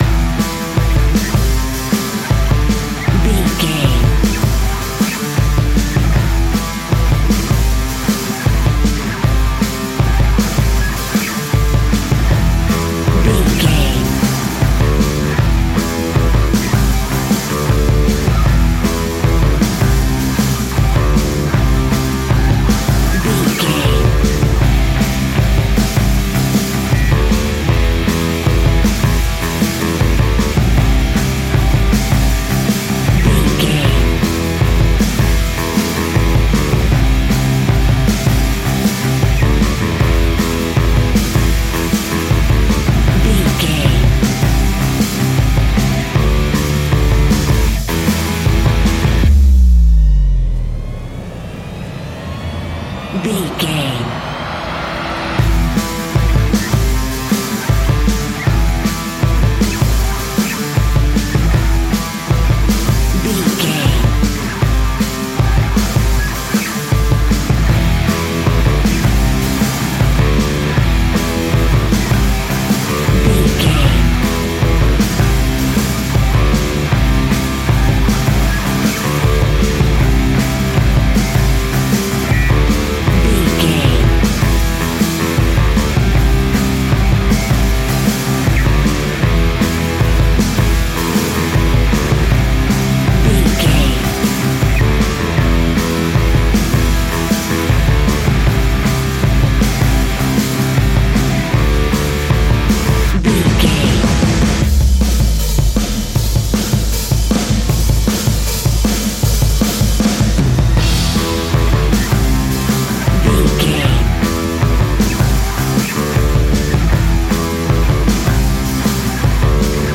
Ionian/Major
A♭
hard rock
heavy rock
distortion
instrumentals